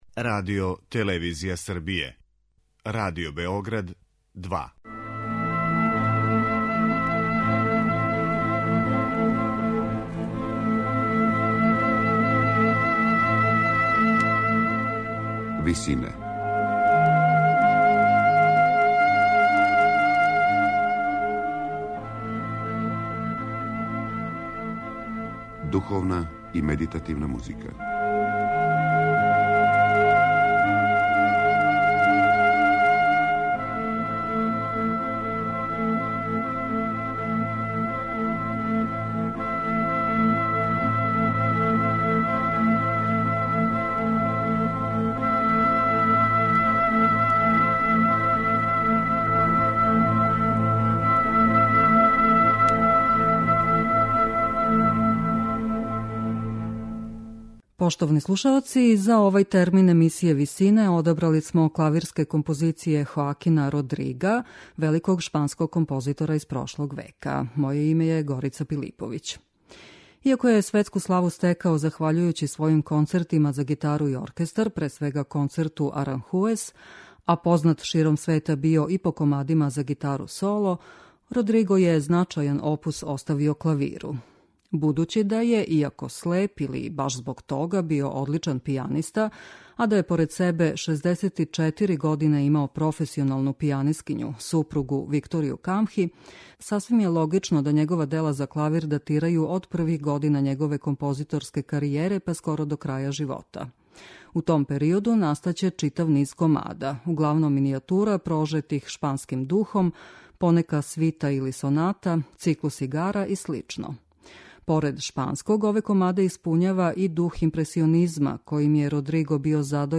Комади за клавир